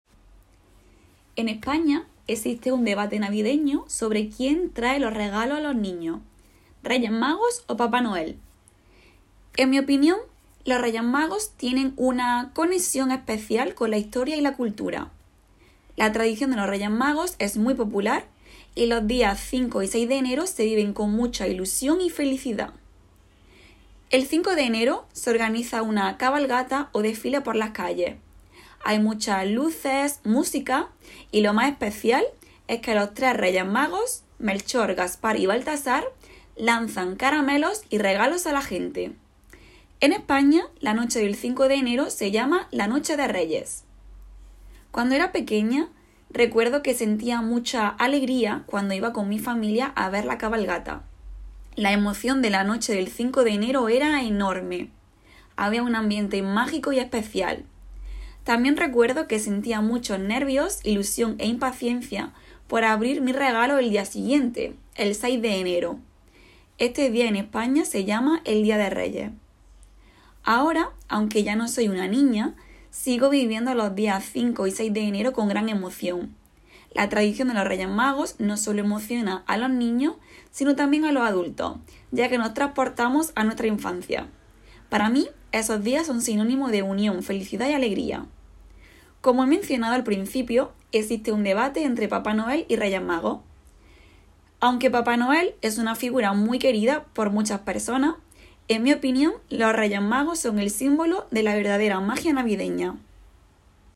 Vous trouverez dans cette nouvelle rubrique de courts enregistrements réalisés par les assistants d’espagnol nommés dans notre académie, classés par thèmes et niveau du CECRL.